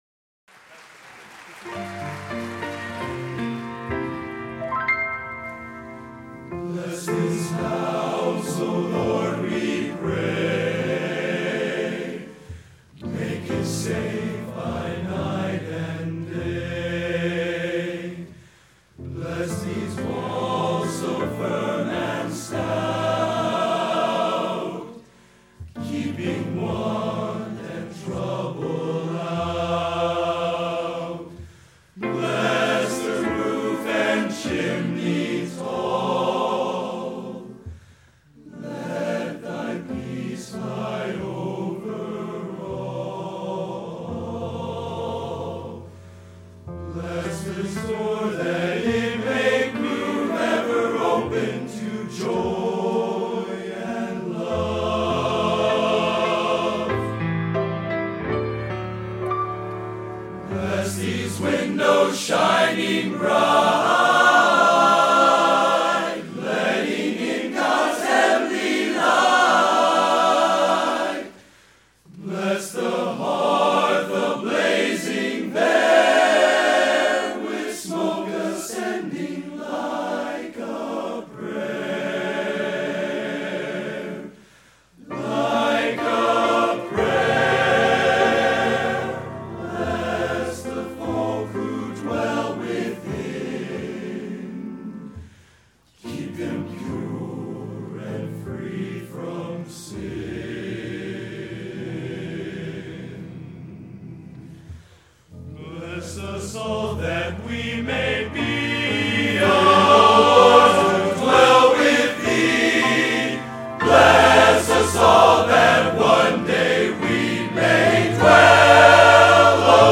Collection: Northrop High School 10/21/2001
Location: Northrop High School, Fort Wayne, Indiana